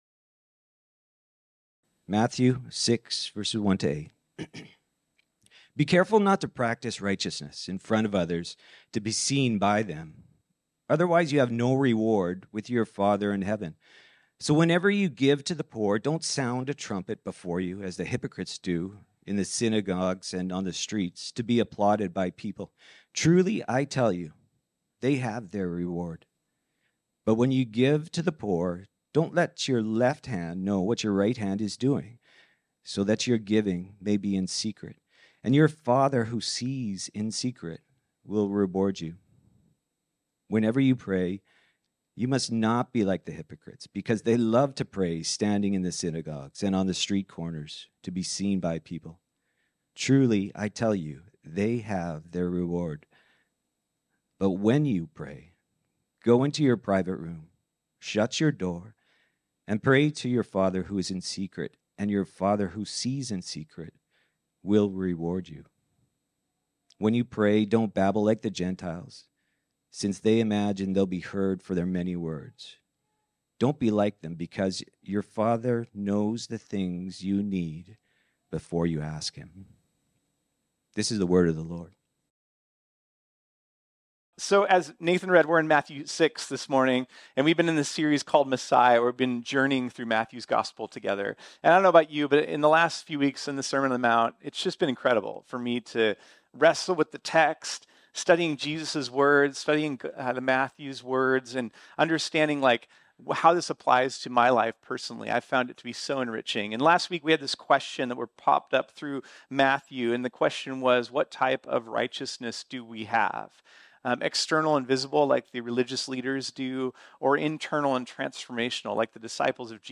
This sermon was originally preached on Sunday, February 18, 2024.